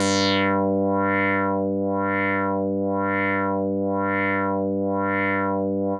Index of /90_sSampleCDs/Trance_Explosion_Vol1/Instrument Multi-samples/LFO Synth
G3_lfo_synth.wav